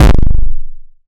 Index of /m8-backup/M8/Samples/breaks/breakcore/earthquake kicks 2
slammer kick.wav